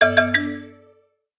06361 Xylo completed ding
chime complete ding info mallet message notification xylo sound effect free sound royalty free Sound Effects